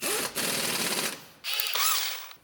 sfx_drill.ogg